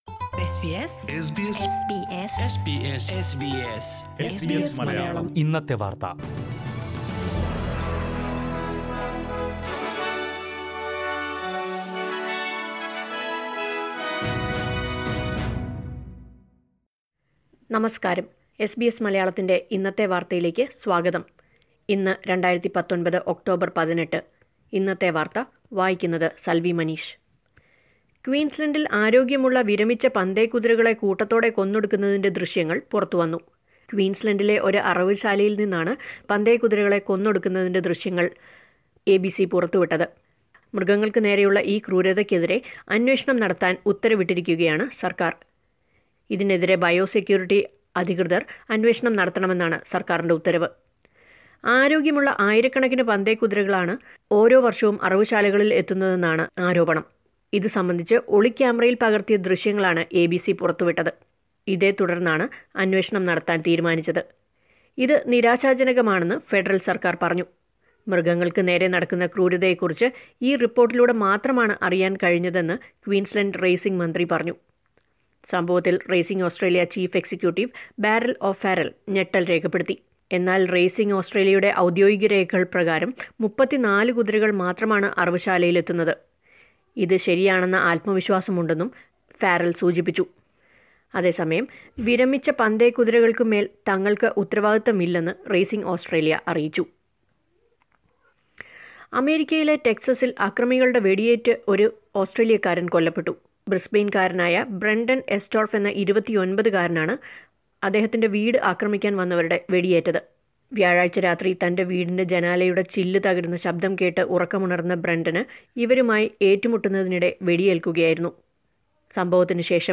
SBS Malayalam Today's News: October 18, 2019